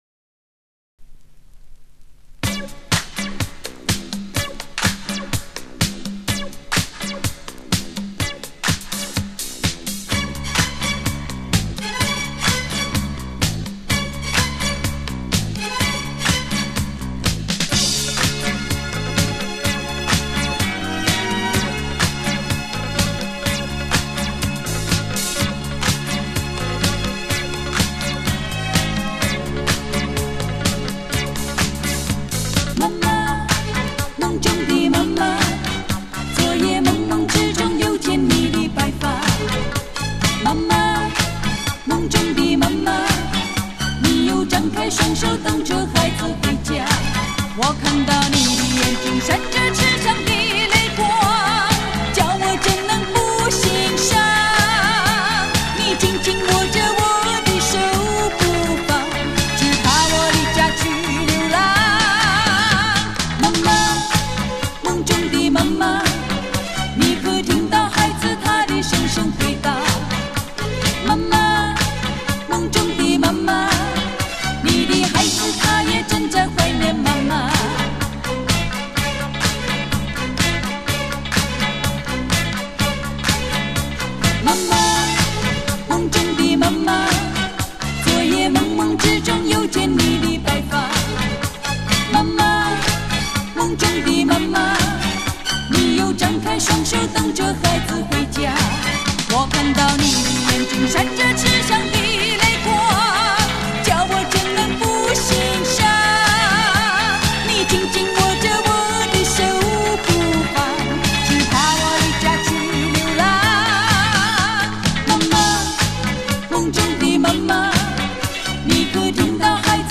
LP版
流派: 流行
介质: LP唱片转录WAV/MP3.320K